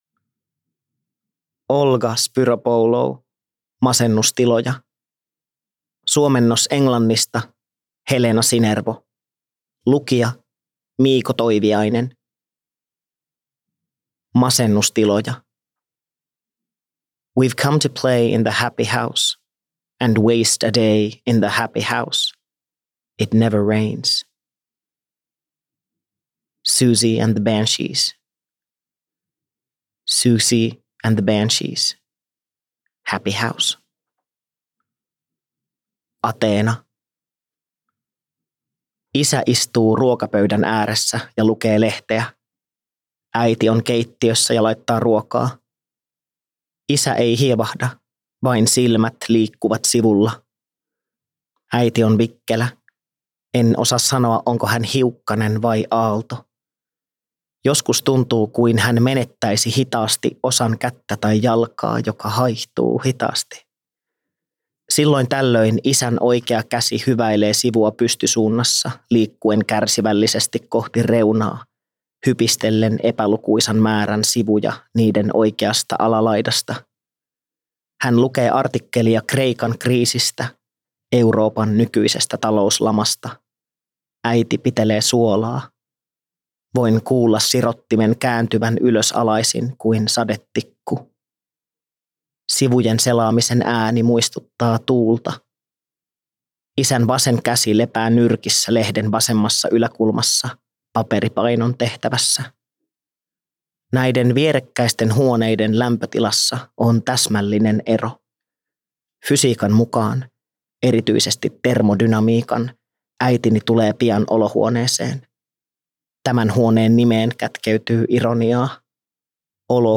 From this page, you can listen to all the texts of the Sulava book in English or in Finnish like an audio book.